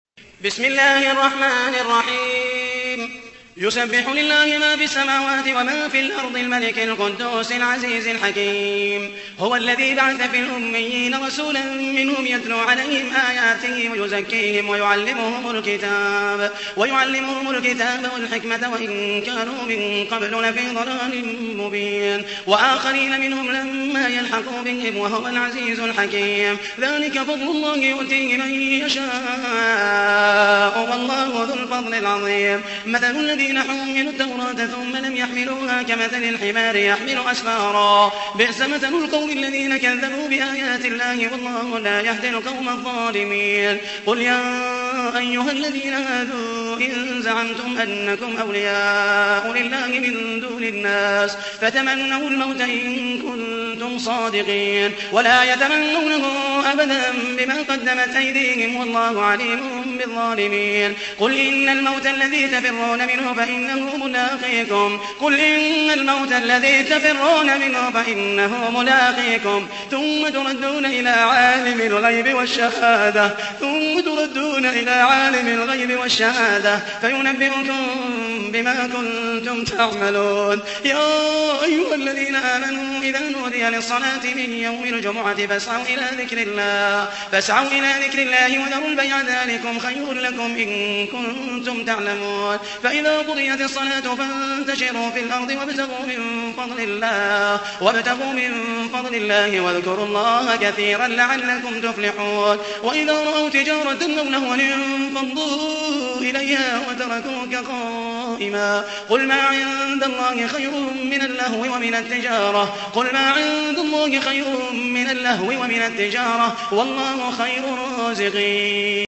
سورة الجمعة / القارئ محمد المحيسني / القرآن الكريم / موقع يا حسين